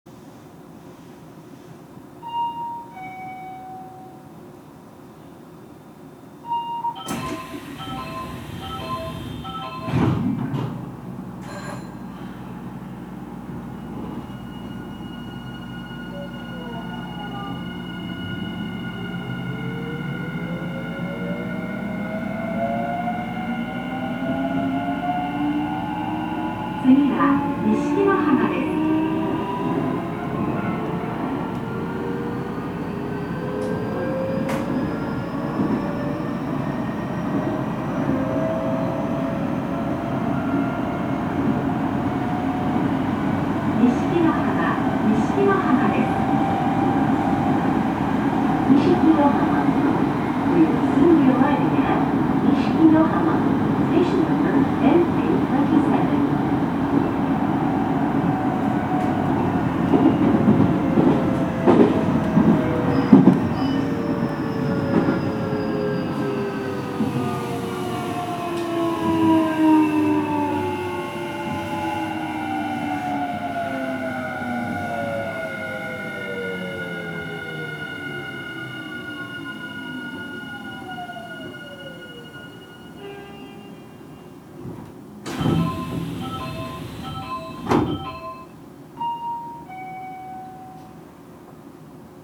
走行機器はIGBT素子によるVVVFインバータ制御で、定格180kWのMB-5091-A2形かご形三相誘導電動機を制御します。
走行音
録音区間：鶴原～二色浜(お持ち帰り)